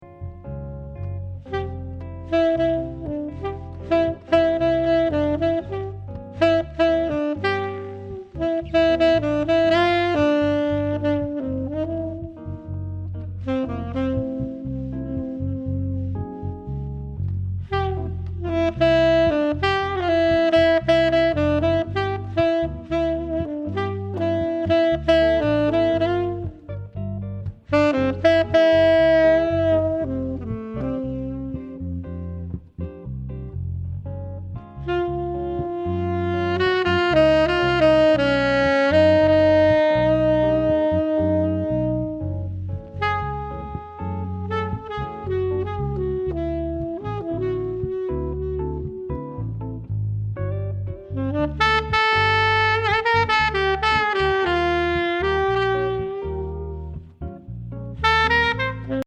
standard Jazz, contemporary or Jazz-fusion